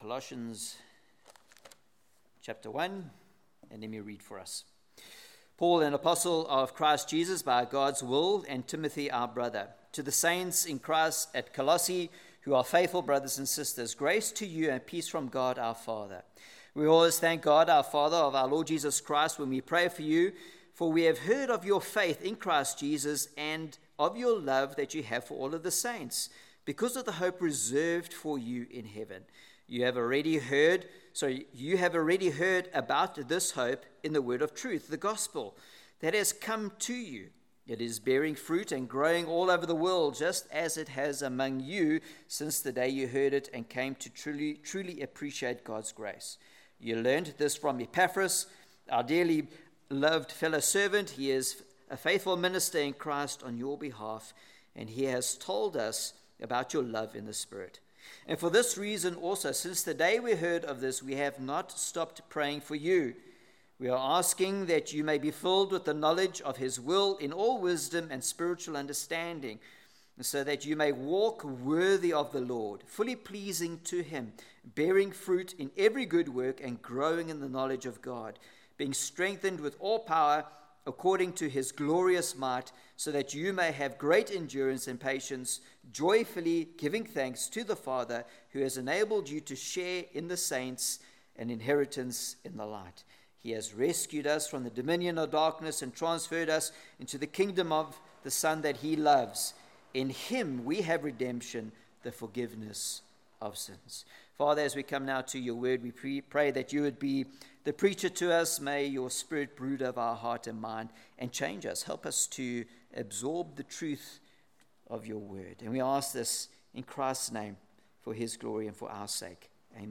Service Type: Sunday Evening